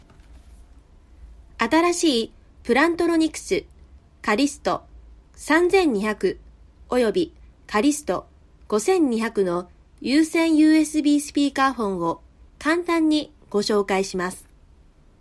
电子课件【自然亲切】